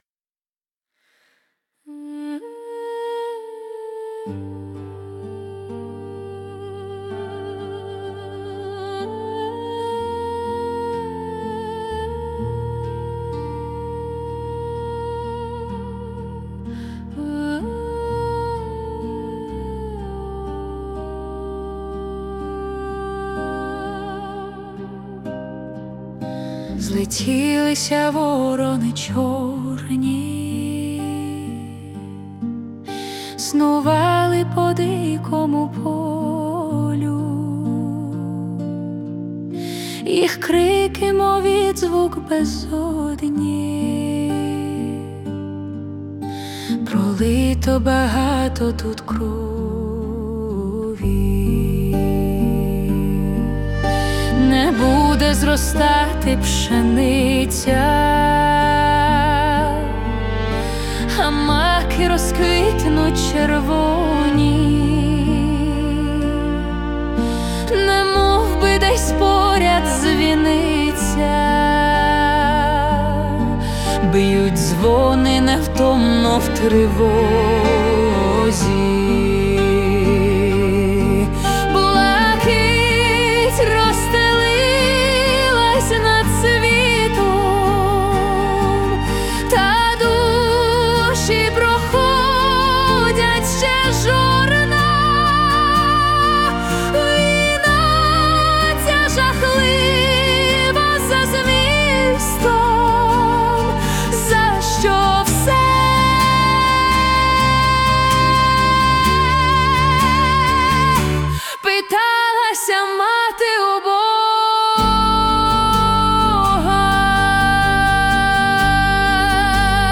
Музична композиція створена за допомогою SUNO AI
17 17 Щемна пісня, торкається душі. hi